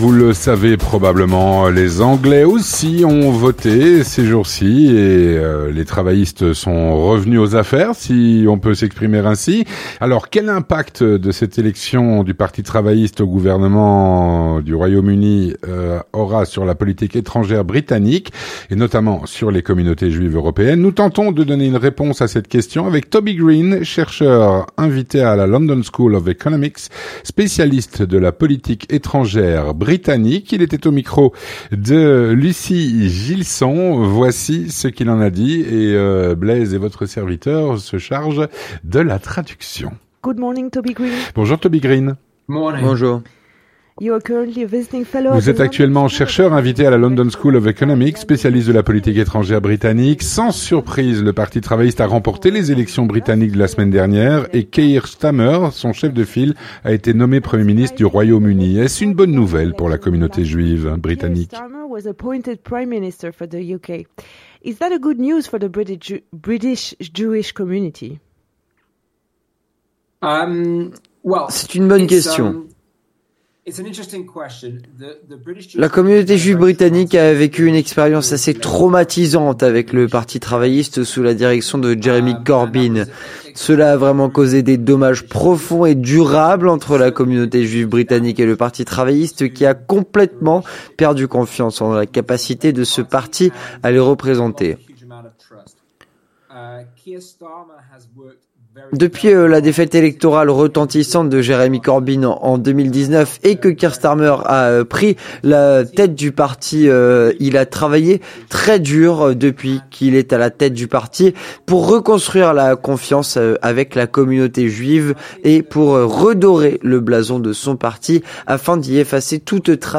Présenté et traduit
Doublé